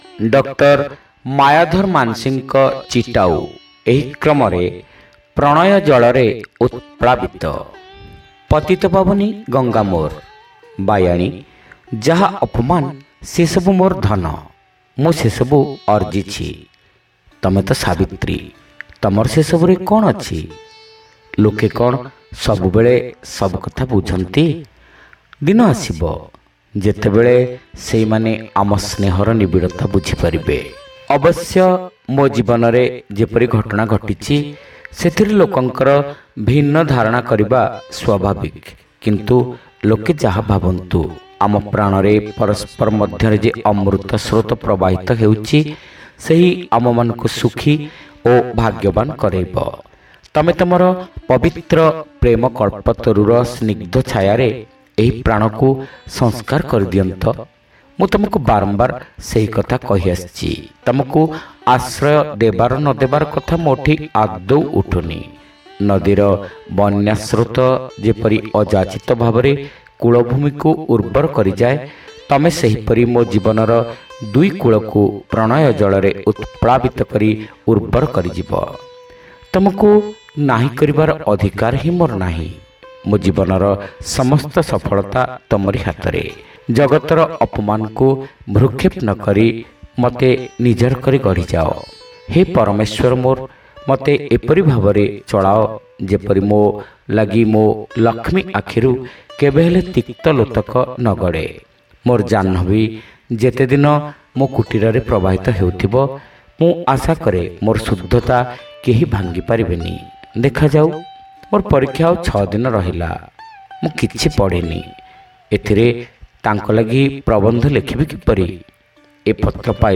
ଶ୍ରାବ୍ୟ ଗଳ୍ପ : ପ୍ରଣୟ ଜଳରେ ଉତ୍‌ପ୍ଳାବିତ